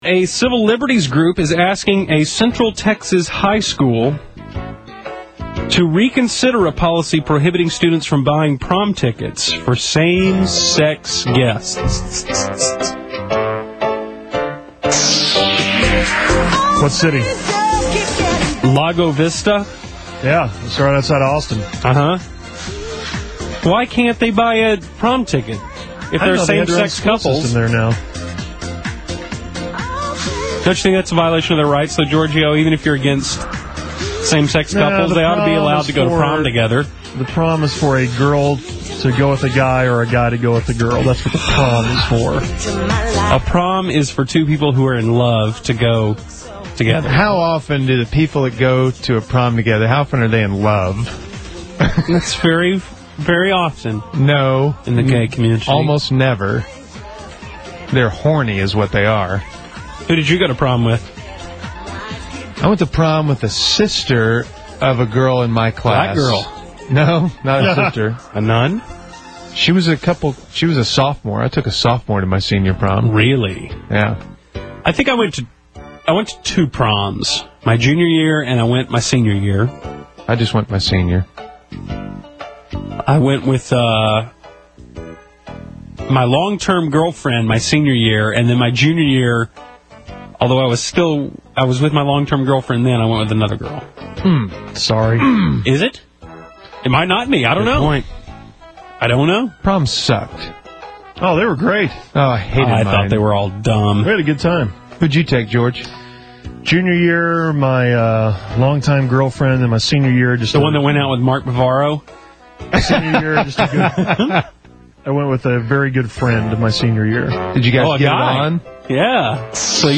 Source: 2004 Internet Stream